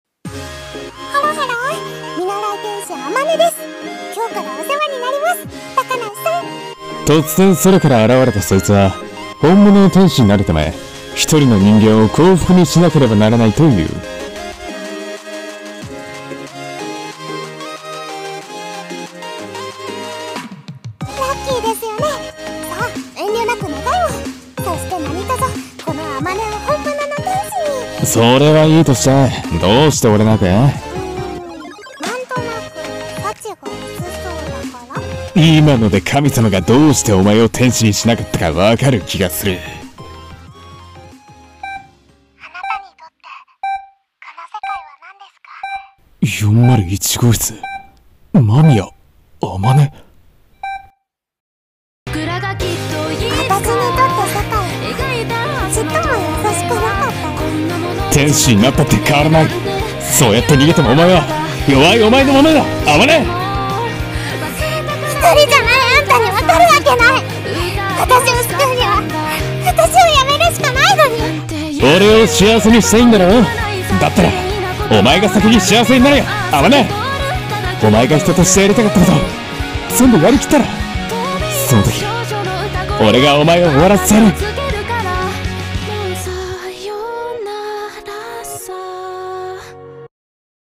アニメPV声劇【見習い（？）天使の幸福論】